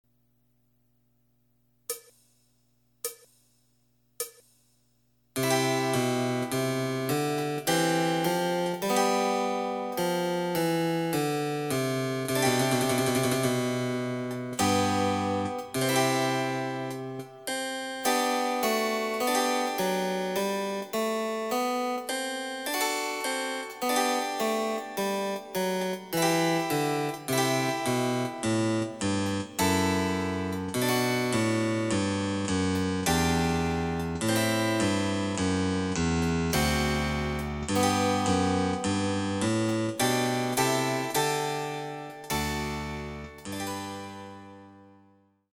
★フルートの名曲をチェンバロ伴奏つきで演奏できる、「チェンバロ伴奏ＣＤつき楽譜」です。
試聴ファイル（伴奏）
デジタルサンプリング音源使用
※フルート奏者による演奏例は収録されていません。